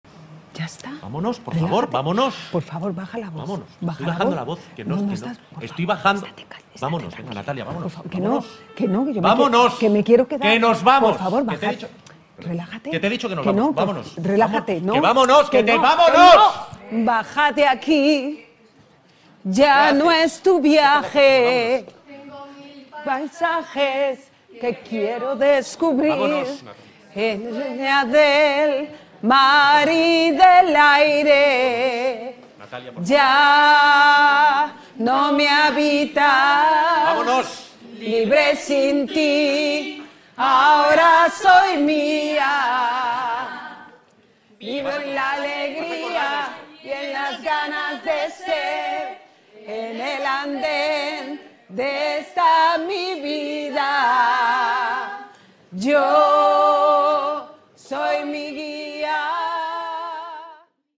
representación teatralizada formato MP3 audio(1,40 MB) simulando una situación de violencia verbal machista, acallada con las voces del coro de la asociación ‘Generando Igualdad’Abre Web externa en ventana nueva, que trabaja por conseguir la igualdad de oportunidades entre hombres y mujeres y la erradicación de la violencia de género.
2.Coroteatralizado.mp3